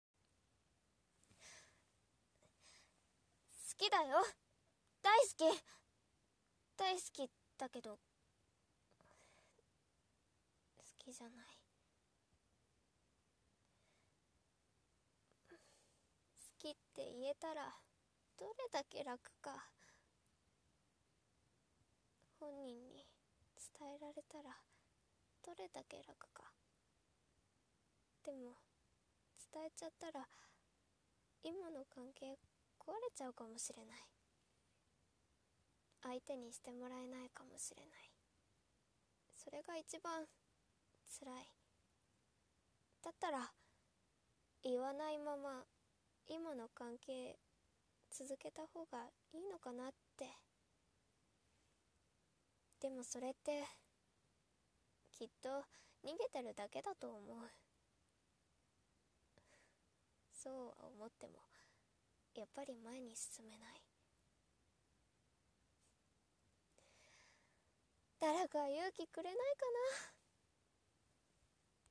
一人声劇